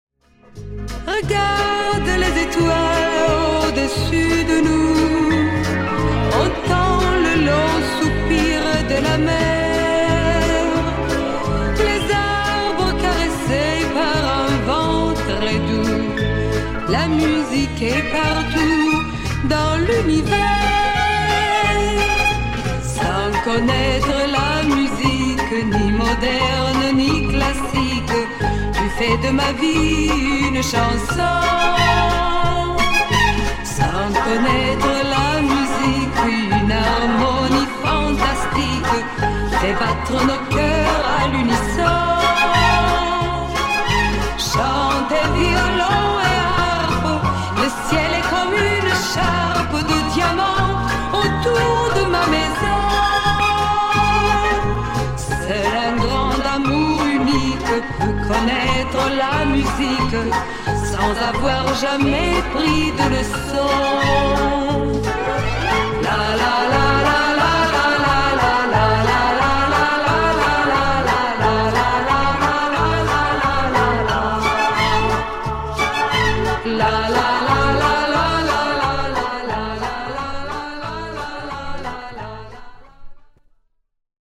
Figura de la canción de los años 60